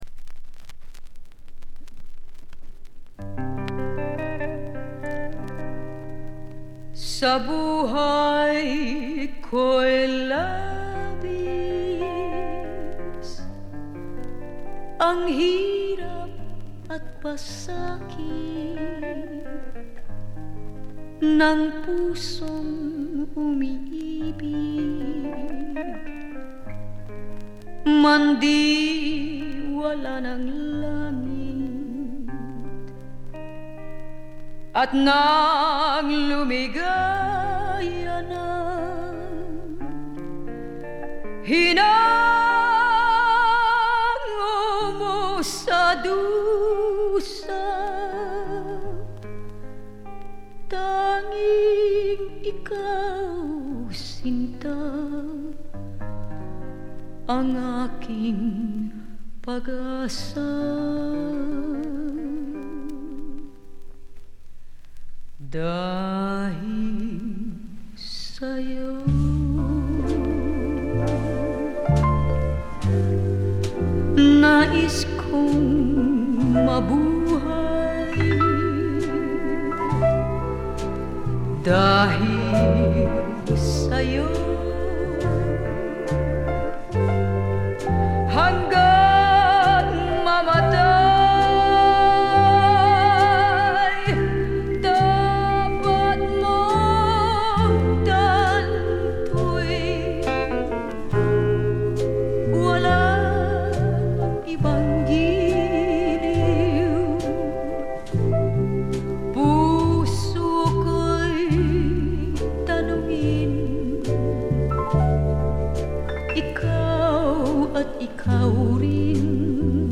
フィリピン出身の女性シンガー
ライブ録音らしくバンドと一体感のあるグルーヴが素晴らしいです。
使用感という意味では新品同様極美品ですが、自主盤らしいプレス起因と思われる軽微なチリプチが少し聴かれます。
試聴曲は現品からの取り込み音源です。